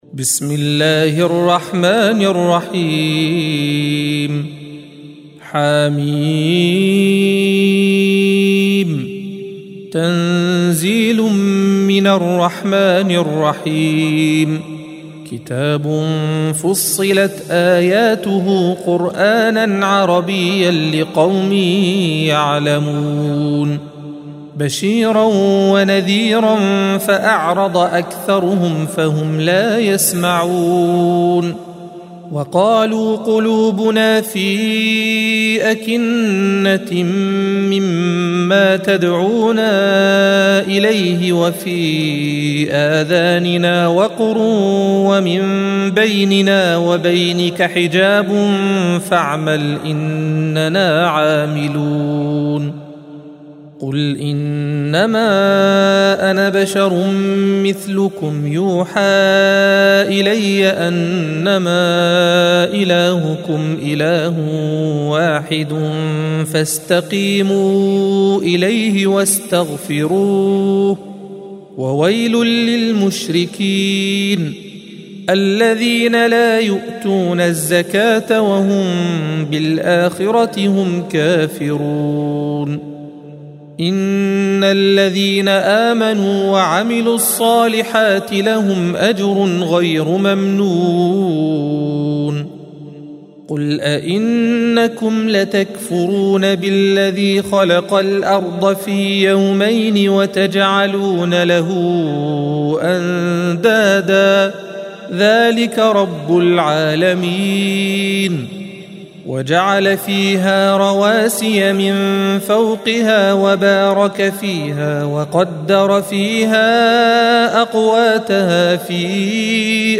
الصفحة 477 - القارئ